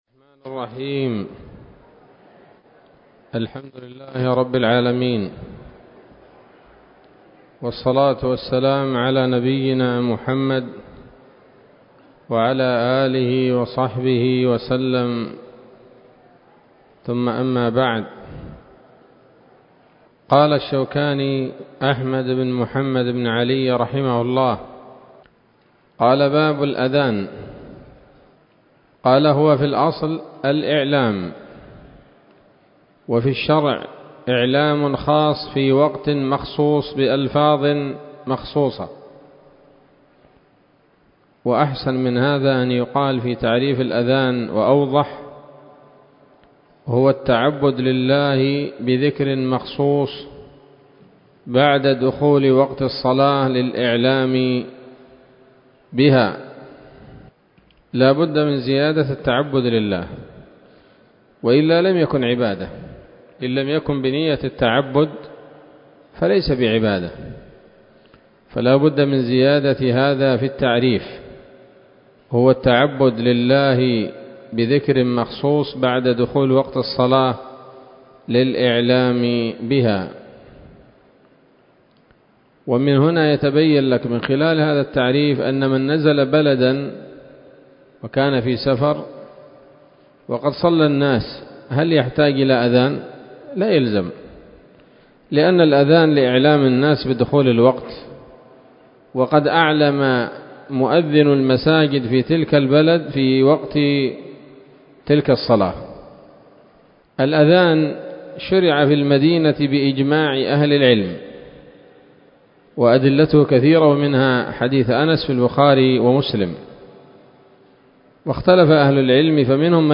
الدرس السادس من كتاب الصلاة من السموط الذهبية الحاوية للدرر البهية